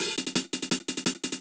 Index of /musicradar/ultimate-hihat-samples/170bpm
UHH_AcoustiHatA_170-05.wav